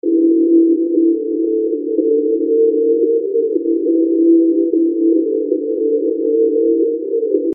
с рояли сняли много низкой середины Нажмите для раскрытия... ниже нижняя середина на гитарном проигрыше, сначала Ваш первый (мясной) вариант, затем второй. Я по-крайней мере слышу так, что чуть-чуть сняли гитару и подняли еще чего-то. Не фатально, но кач с гитары ушел, в т.ч. и из-за этого.